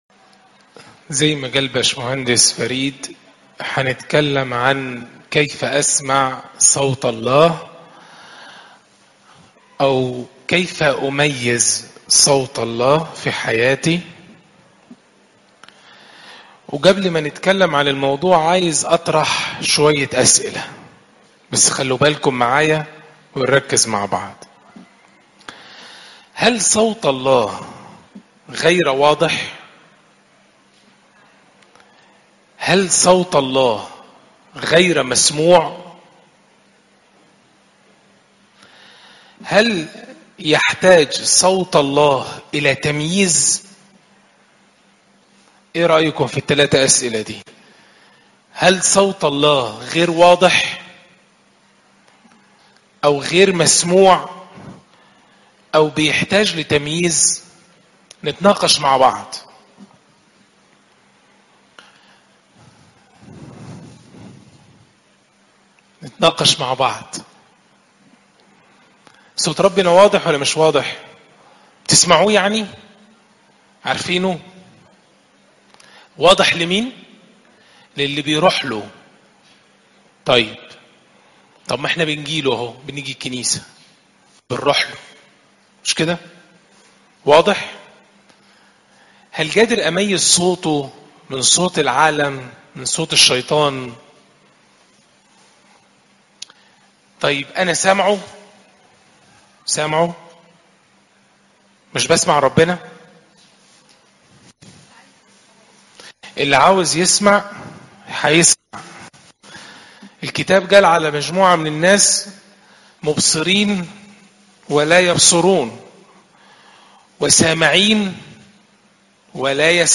عظات المناسبات